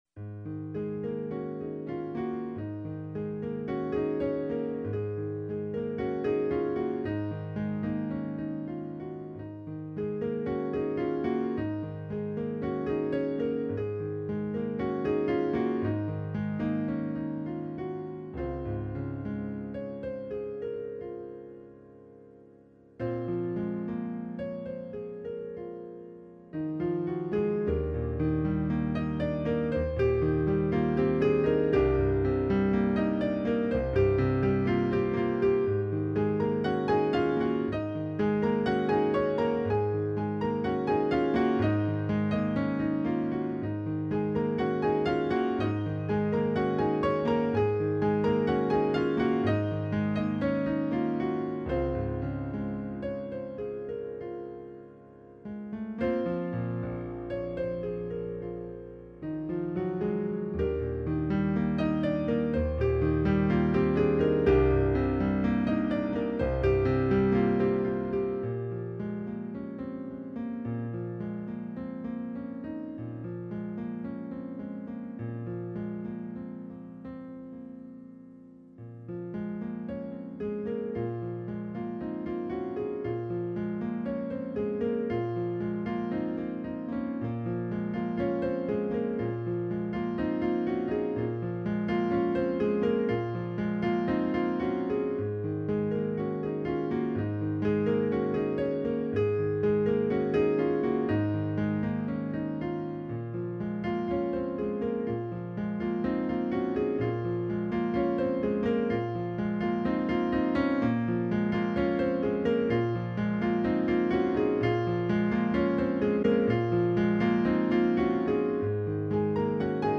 Another simple, melodic song – also with a few versions of similar chords.  Not too many notes, yet memorable.